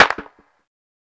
CLAP_LET_IT_LOOSE.wav